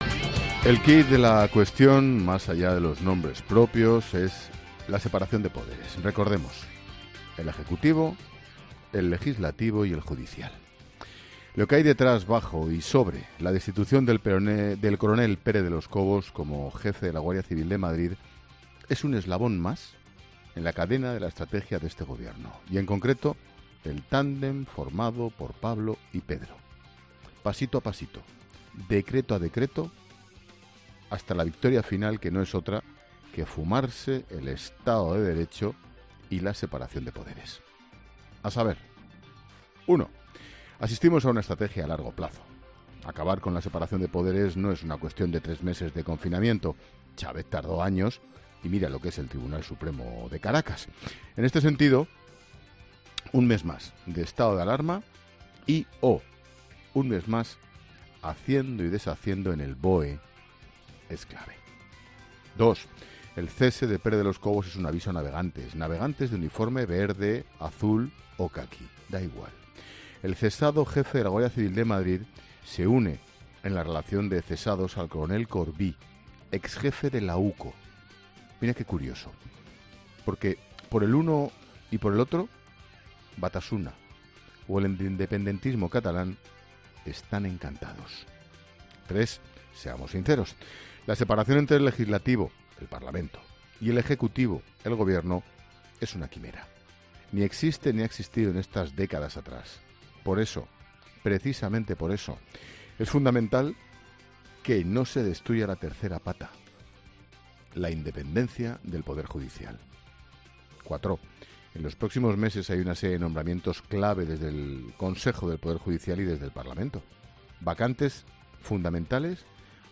8.00 | Carlos Herrera, en su monólogo de HERRERA EN COPE: